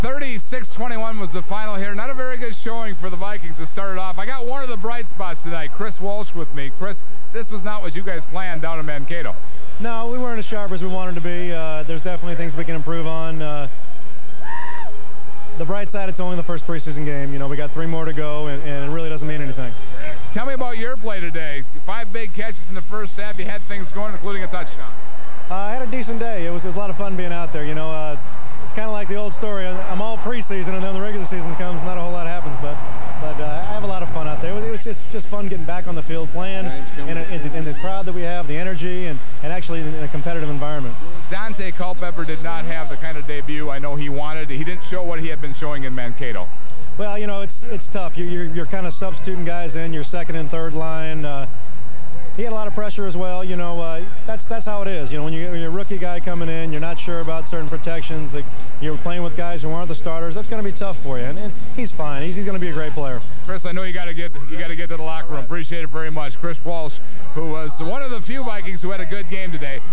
wav file (2.9 MB) or quicktime file (1.4 MB) of the 1:10 interview.